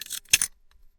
equip.wav